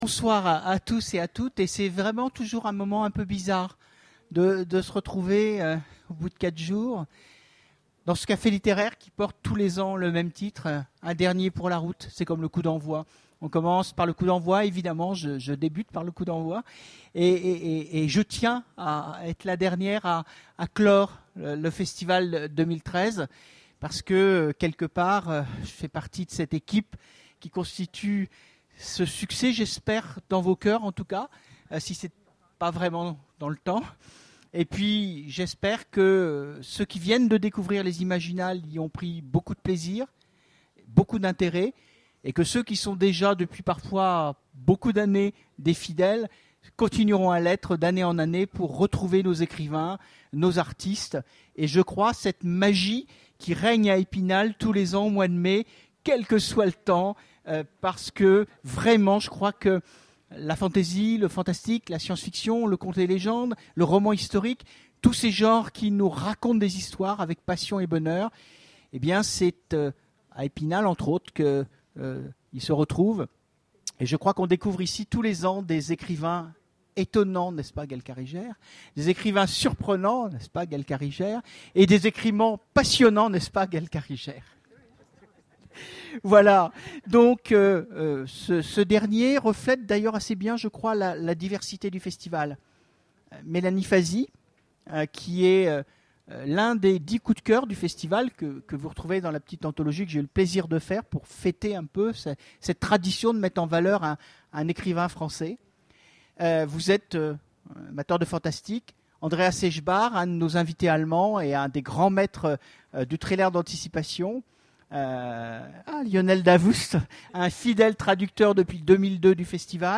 Imaginales 2013 : Conférence Un dernier pour la route !